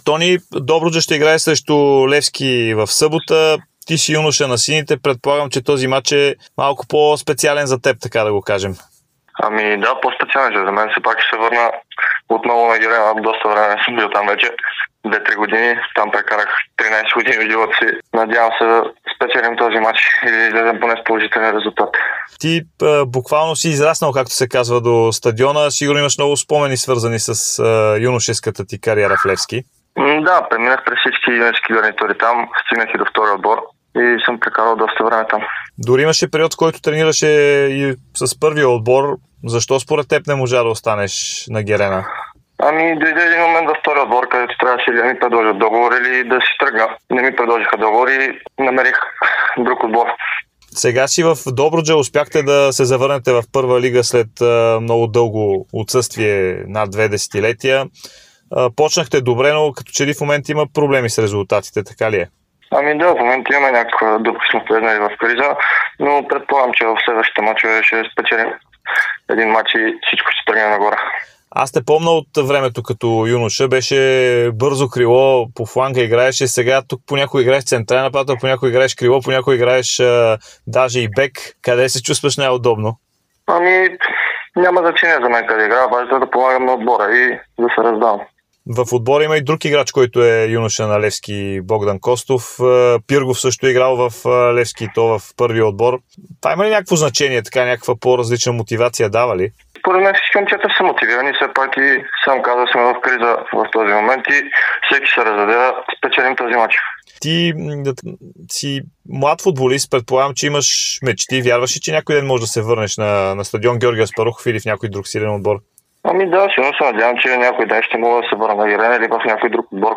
Преди мача между двата тима той говори пред Дарик радио и Dsport.